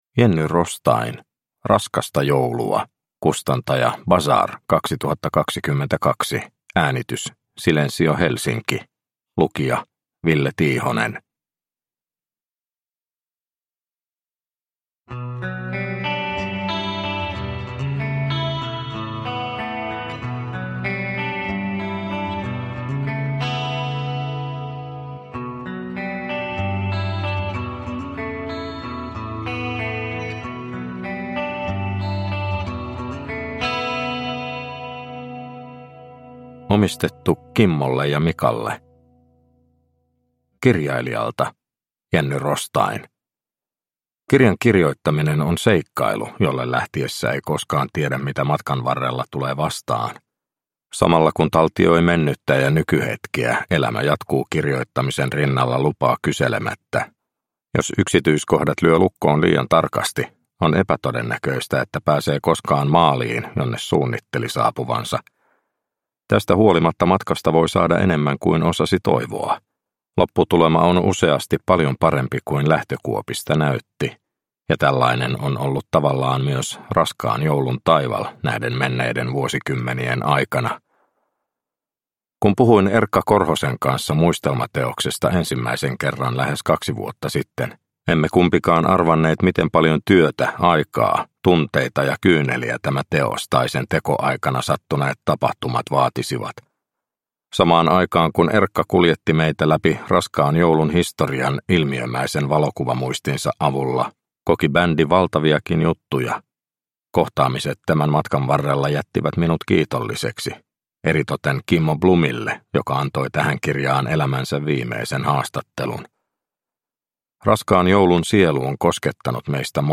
Raskasta Joulua – Ljudbok – Laddas ner